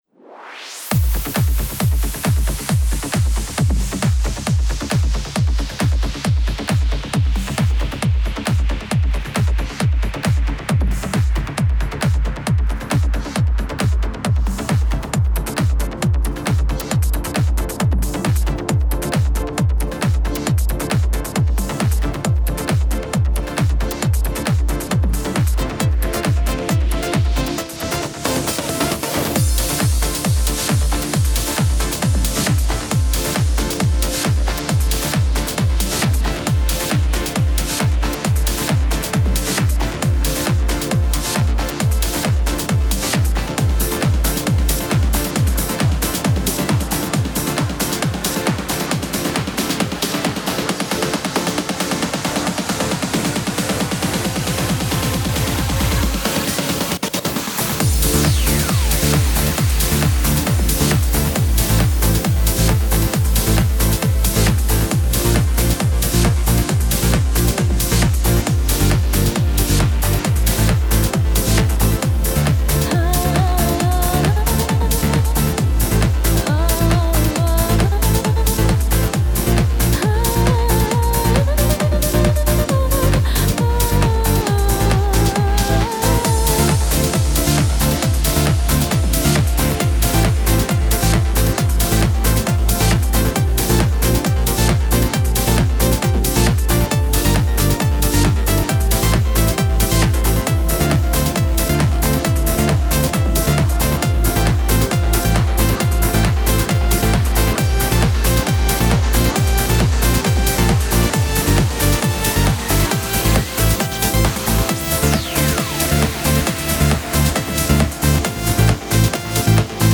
Genre: Progressive.